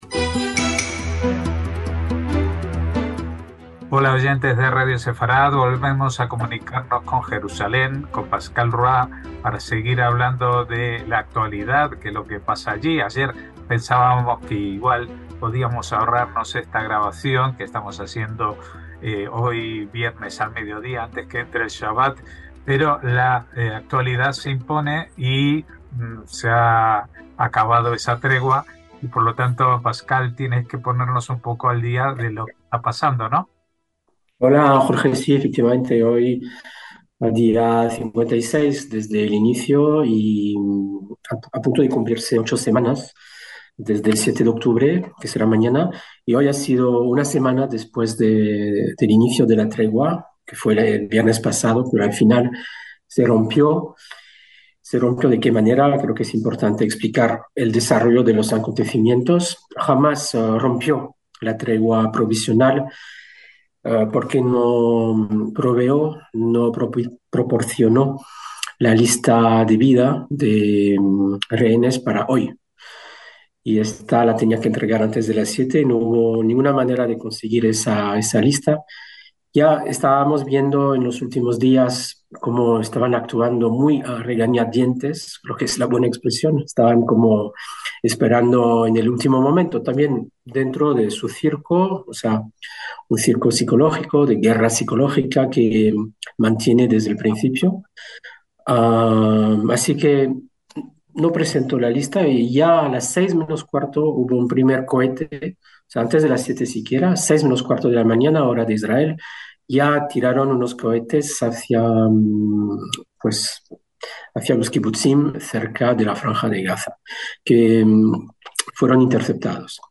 NOTICIAS CON COMENTARIO A DOS – Tras la ruptura de la tregua por Hamás al no proporcionar la lista de rehenes israelíes secuestrados para liberar, han vuelto las hostilidades al terreno.